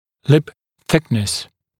[lɪp ‘θɪknəs][лип ‘сикнэс]толщина губы (губ)